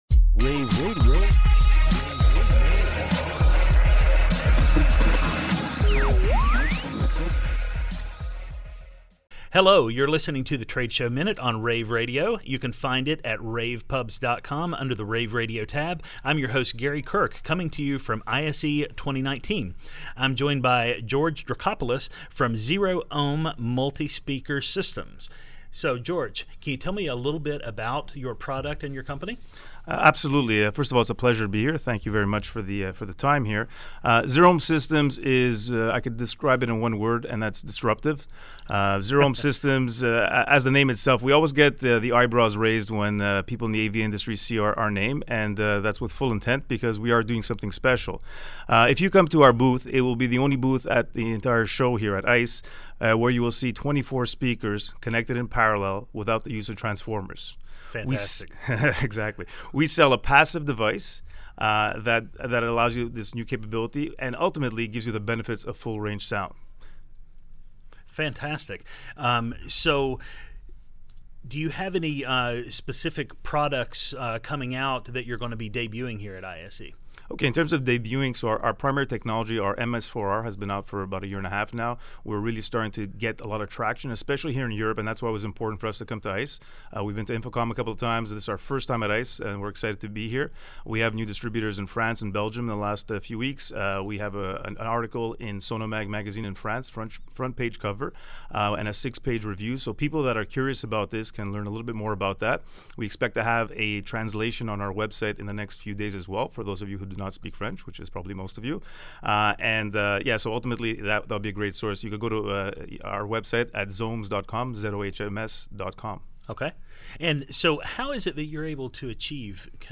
interviews
February 5, 2019 - ISE, ISE Radio, Radio, rAVe [PUBS], The Trade Show Minute,